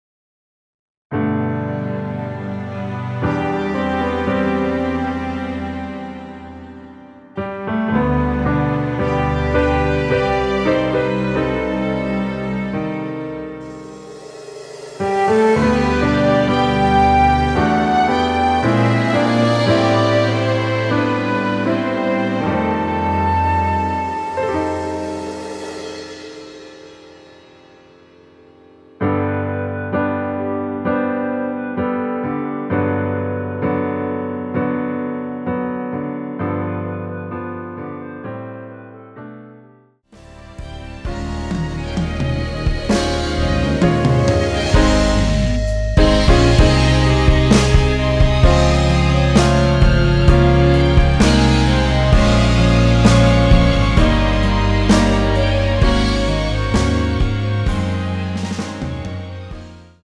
원키에서(-2)내린 멜로디 포함된 MR입니다.
◈ 곡명 옆 (-1)은 반음 내림, (+1)은 반음 올림 입니다.
앞부분30초, 뒷부분30초씩 편집해서 올려 드리고 있습니다.